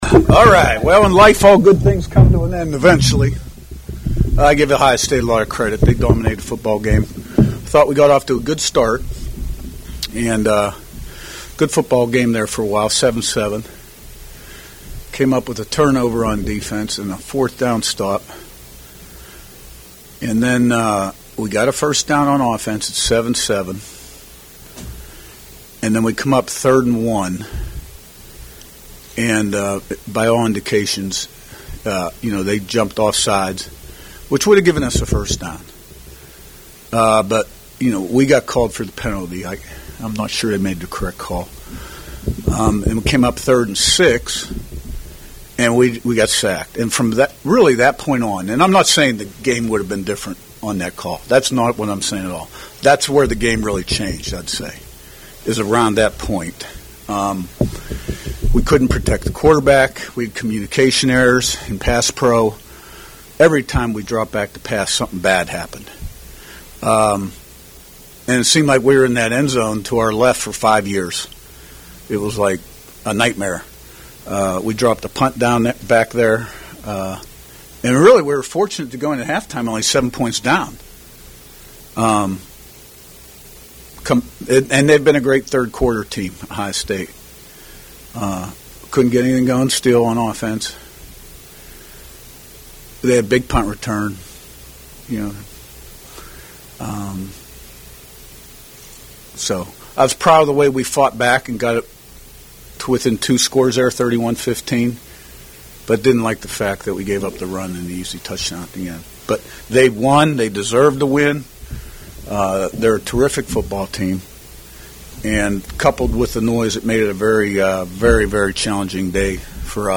Indiana Hoosiers Postgame Press Conference with head coach Curt Cignetti after loss at #2 Ohio State, 38-15